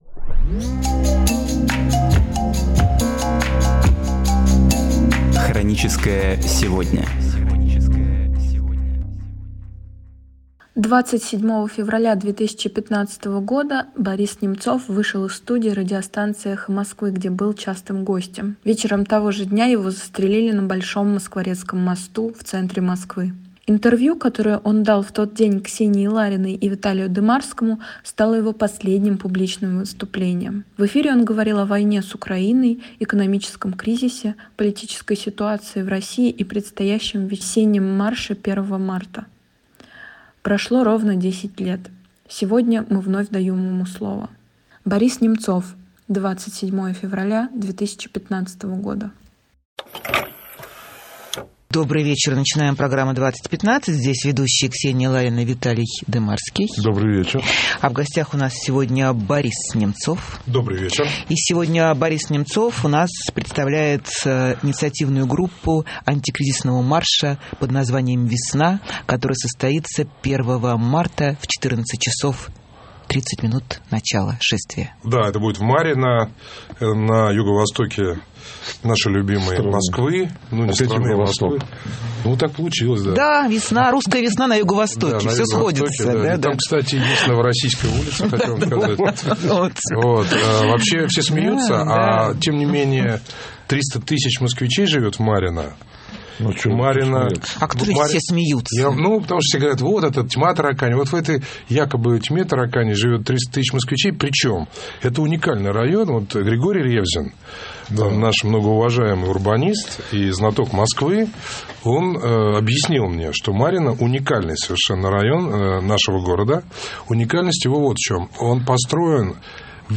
Последнее интервью Немцова